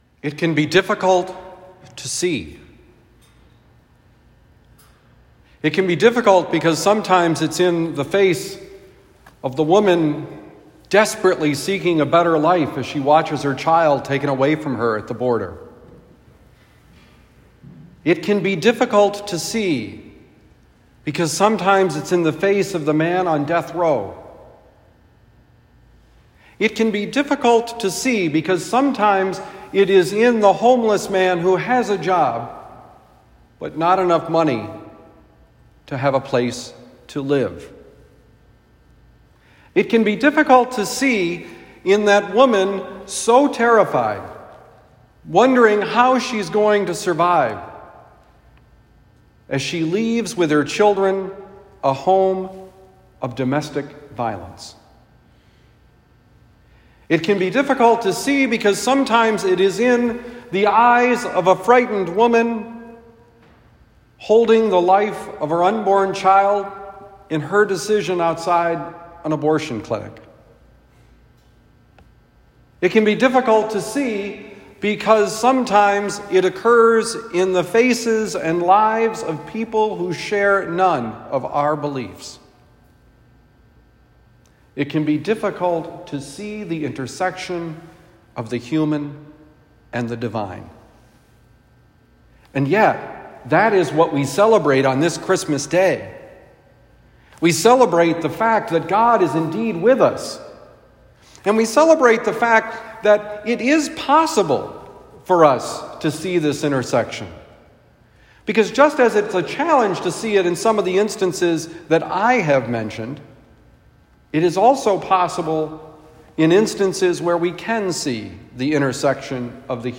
Homily for Christmas Day Mass
Given at Saint Dominic Priory, Saint Louis, Missouri.